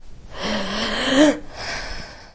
Project LCS » 9 veryheavybreath
描述：You can hear the breath of a female. It has been recorded in a recording classroom at Pompeu Fabra University.
标签： campusupf UPFCS14 inhale breath breathing heavy